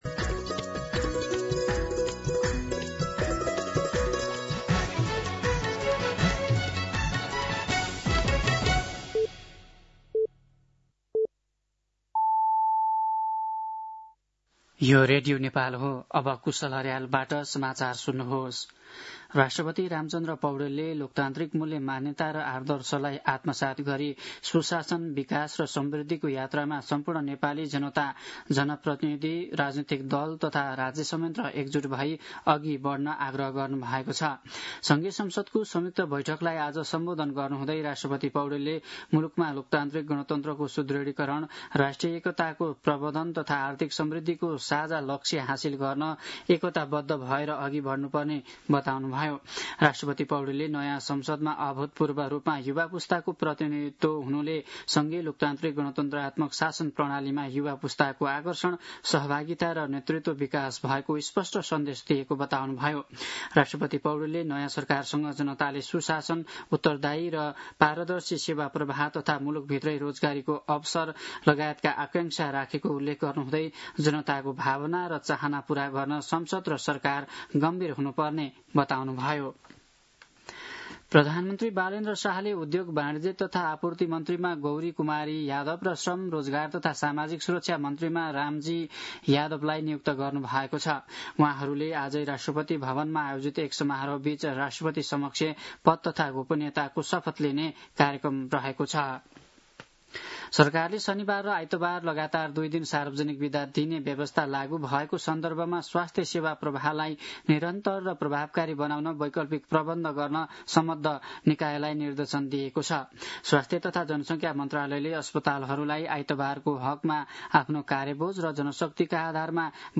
दिउँसो ४ बजेको नेपाली समाचार : २७ चैत , २०८२
4-pm-News-12-27.mp3